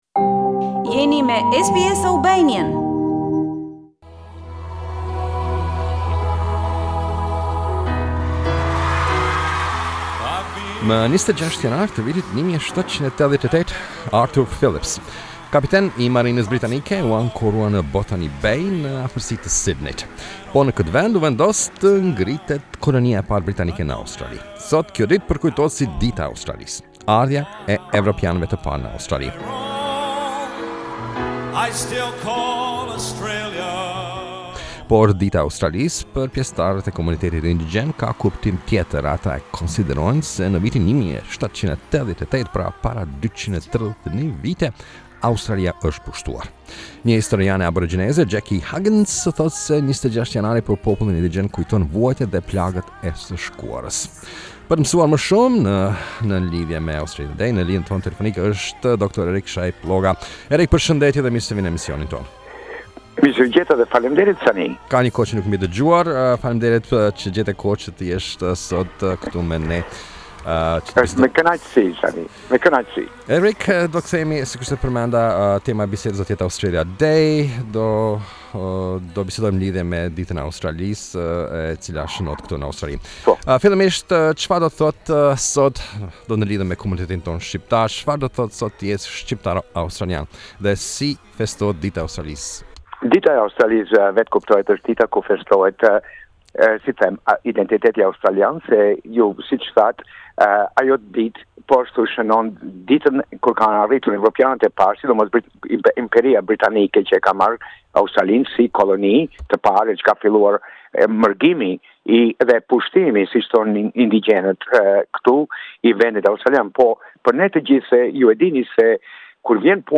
Australia Day - Interview